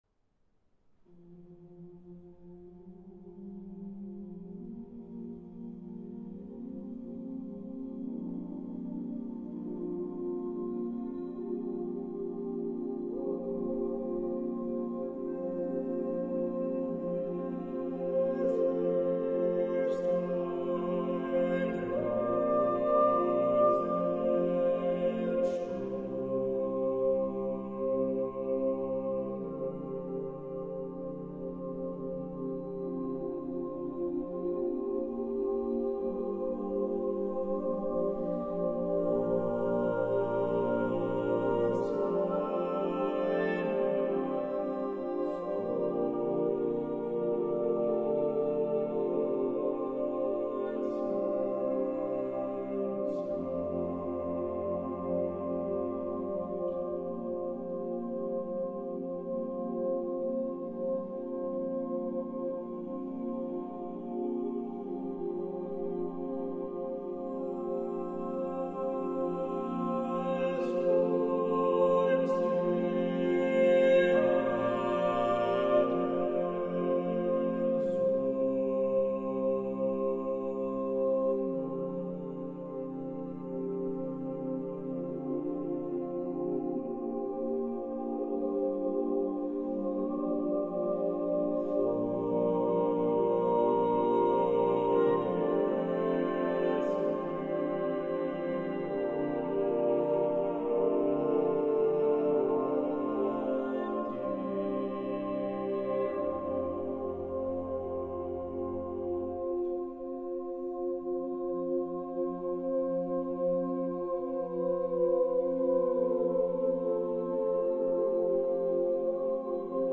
Voicing: SATB Double Choir